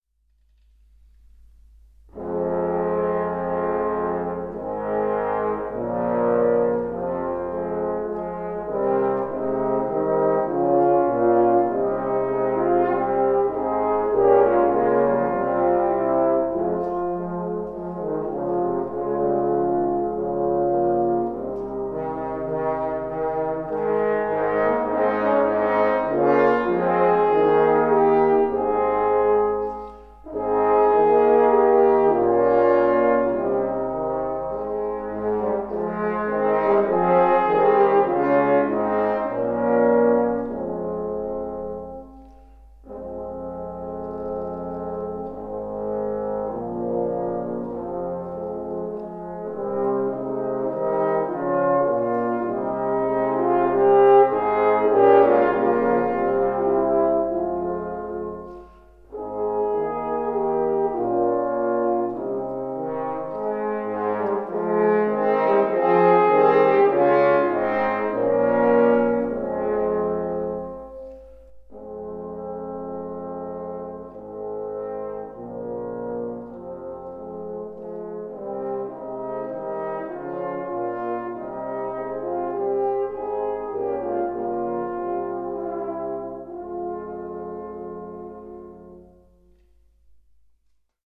3-4 Besetzung: 4 Hörner Artikelnr.